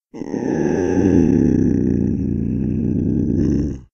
Soundscape Overhaul / gamedata / sounds / monsters / cat / c2_threat_1.ogg
c2_threat_1.ogg